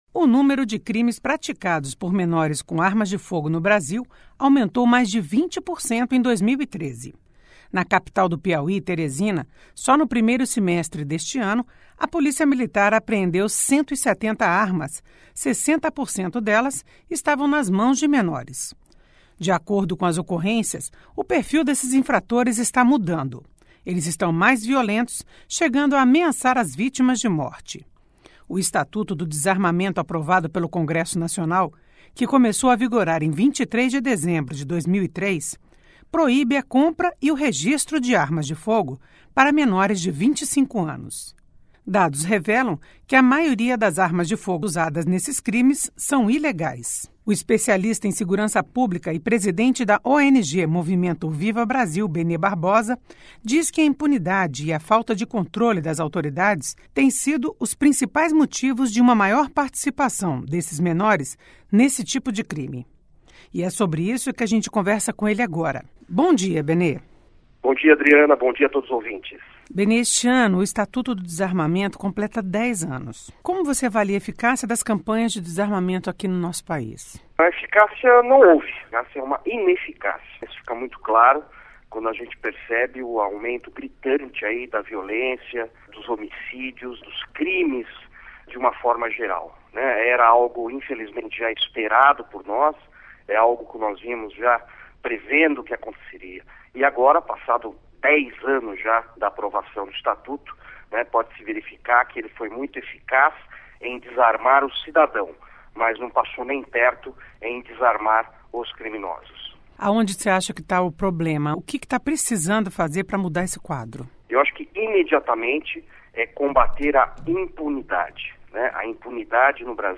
Entrevista: Aumento dos crimes praticados por menores com arma de fogo